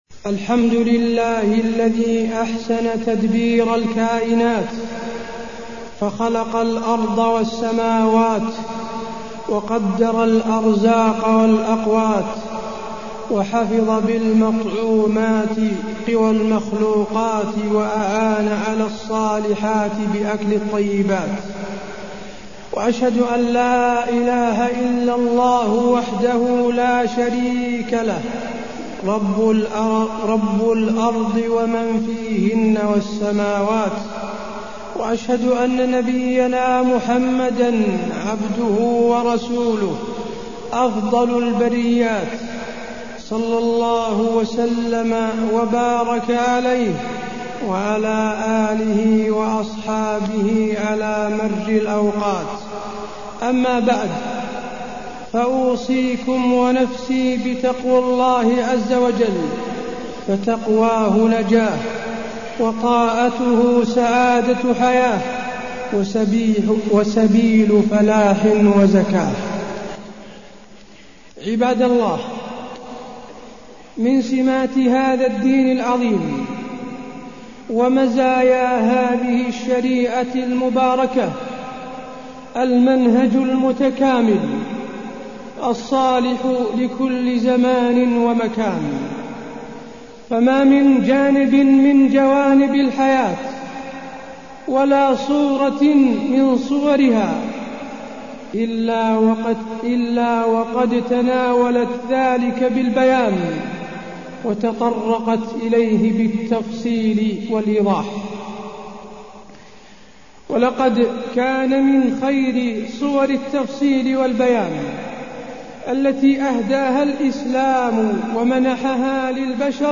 تاريخ النشر ٢٨ شوال ١٤٢٠ هـ المكان: المسجد النبوي الشيخ: فضيلة الشيخ د. حسين بن عبدالعزيز آل الشيخ فضيلة الشيخ د. حسين بن عبدالعزيز آل الشيخ هديه صلى الله عليه وسلم في الأكل والشرب